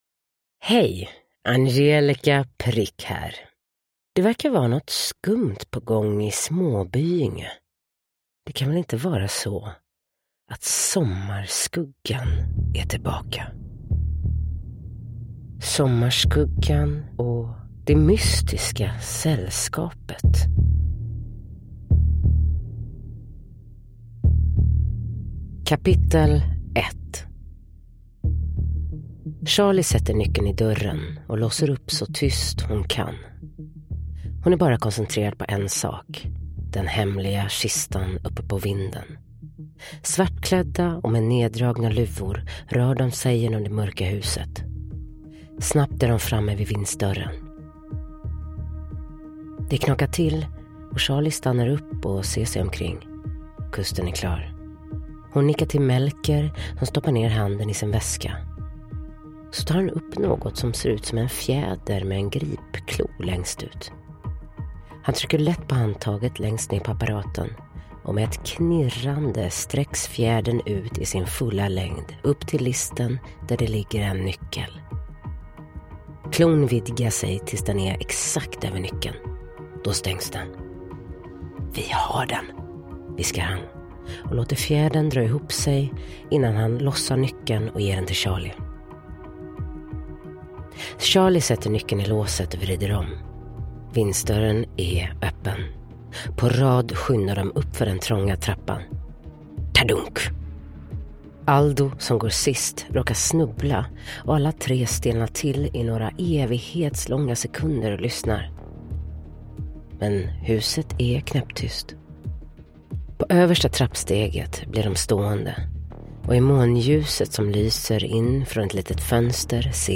Det mystiska sällskapet – Ljudbok